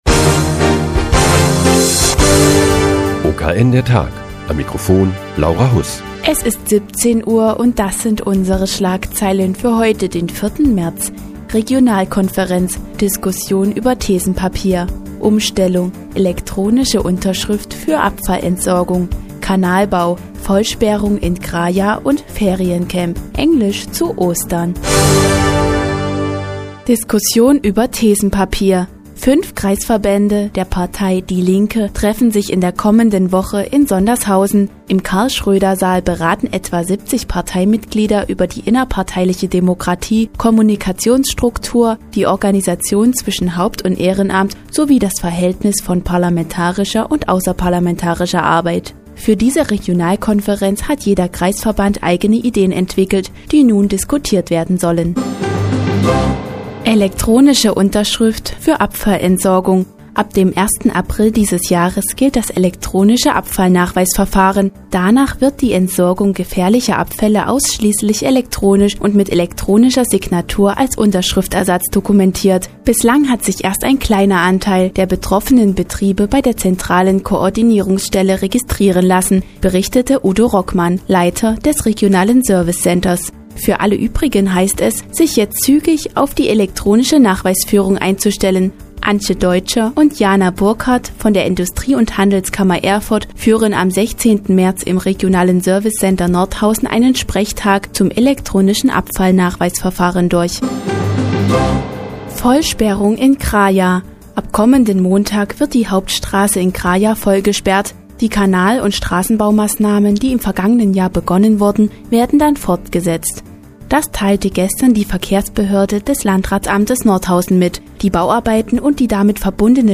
Die tägliche Nachrichtensendung des OKN ist nun auch in der nnz zu hören. Heute geht es um die Vollsperrung in Kraja und das Englisch-Camp während der Osterferien.